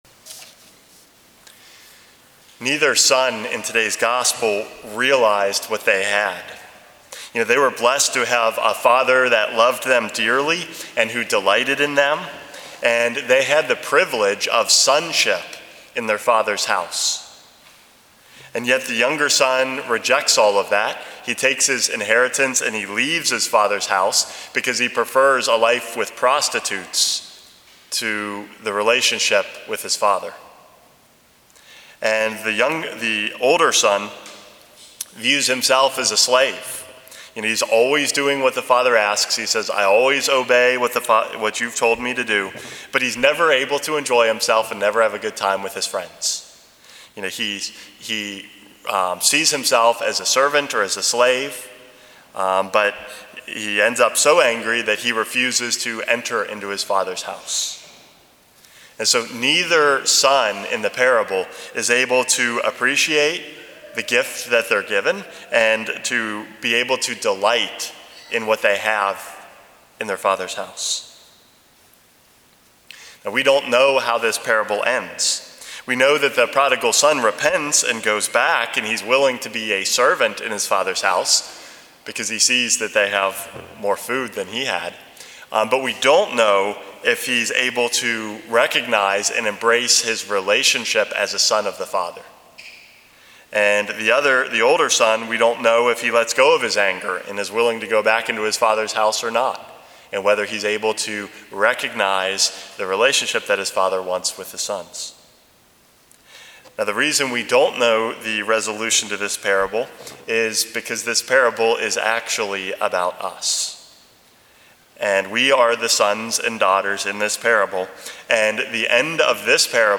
Homily #442 - Finding our Father's House